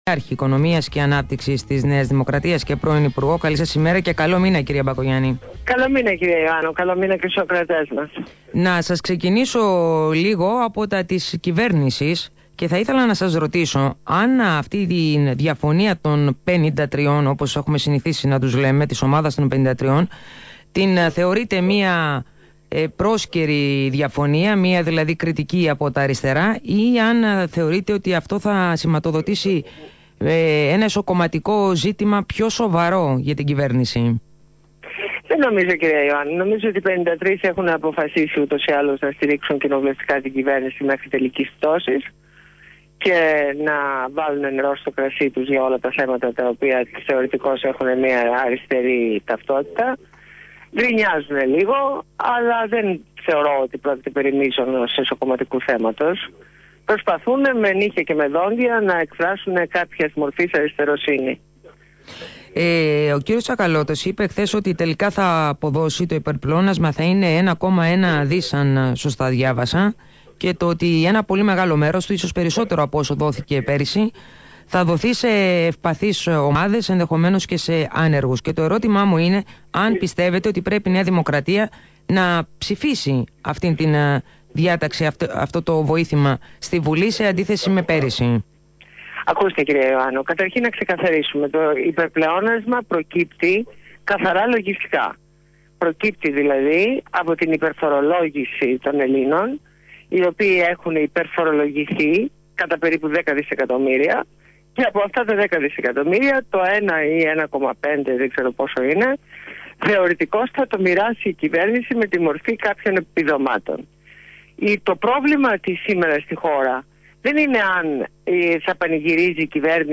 Συνέντευξη στο ραδιόφωνο του ALPHA